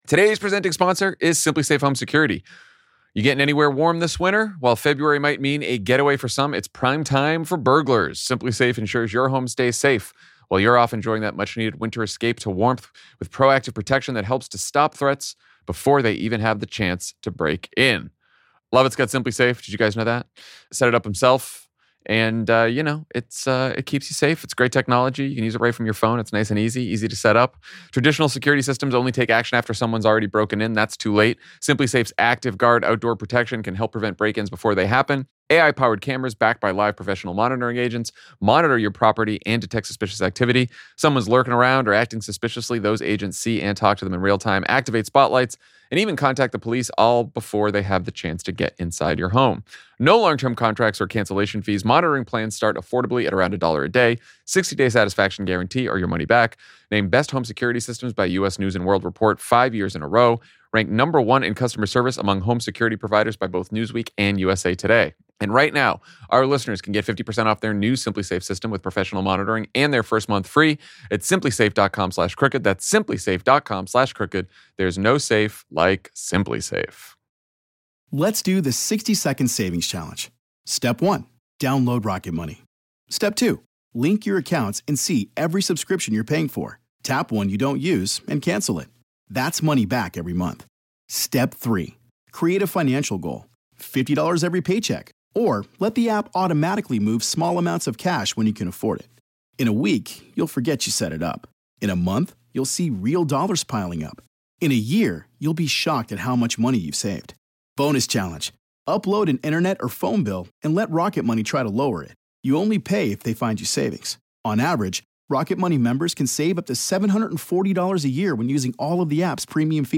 Live from Melbourne, Jon, Lovett, Tommy, and Dan react to Democrats’ decision to stand firm on ICE funding and force a shutdown of the Department of Homeland Security, Pam Bondi’s epic meltdown in front of the House Judiciary Committee, Megyn Kelly’s unhinged response to the Bad Bunny halftime show, and what really happened with the the laser weapon that shut down El Paso airspace. Then, the Australian crowd and American hosts attempt to answer questions from each other's citizenship test.